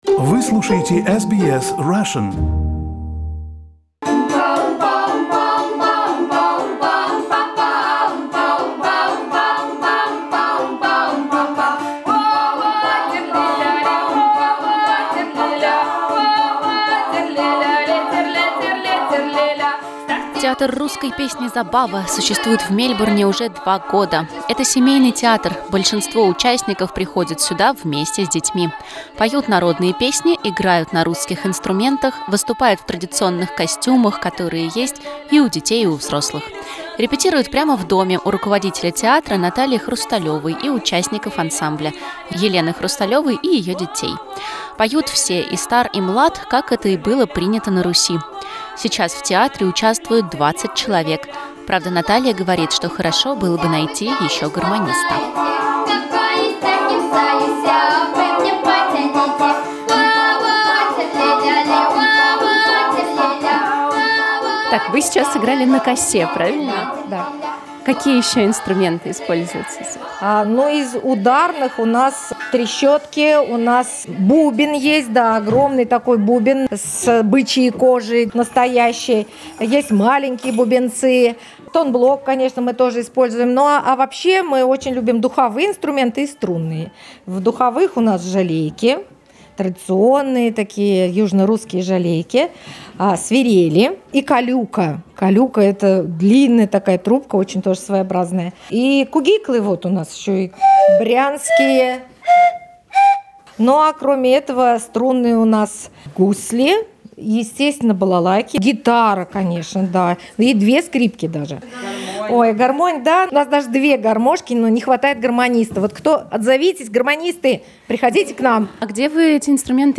Поют народные песни, играют на русских инструментах, выступают в традиционных костюмах, которые есть и у детей, и у взрослых.
Поют все: и стар, и млад, как это и было принято на Руси. Слушайте репортаж с репетиции ансамбля.